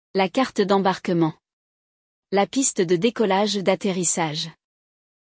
Hoje, vamos ouvir a conversa entre uma turista que acaba de desembarcar na França e um agente de imigração.